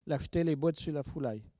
Localisation Saint-Hilaire-de-Riez
Catégorie Locution